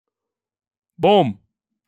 Boom